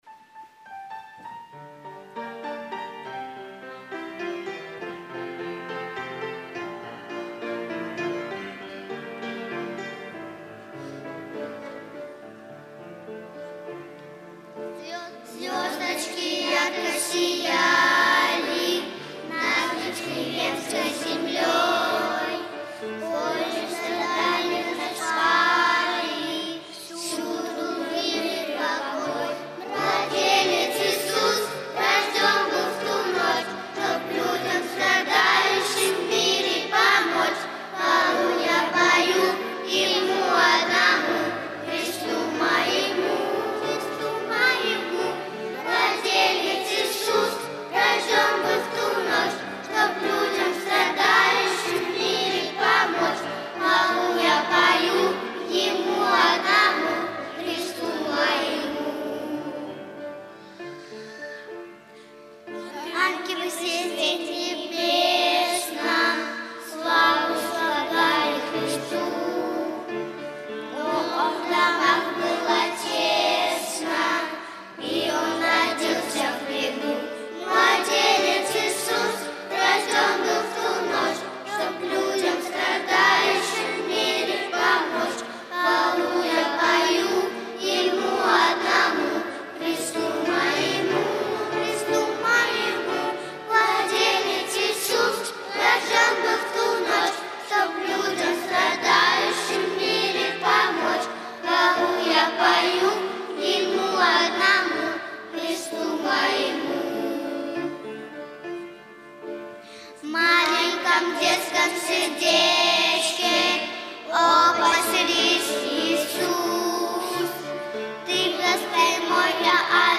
Богослужение 29.01.2023
Звездочки ярко сияли - Благословение (Пение)[